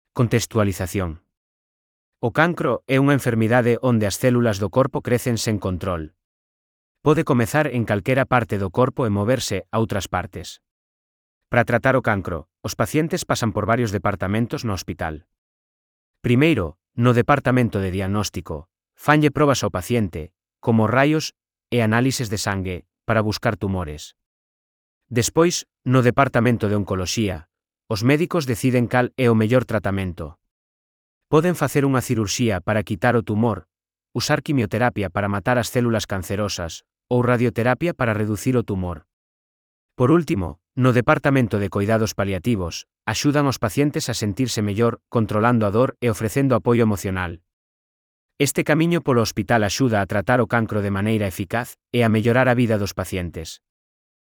Elaboración propia, coa ferramenta Narakeet. Ampliación sobre o cancro: contextualización.